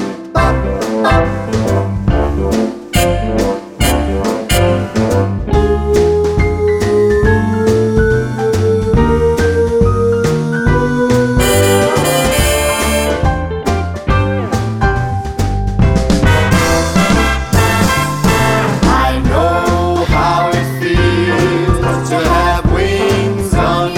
no Backing Vocals Crooners 2:44 Buy £1.50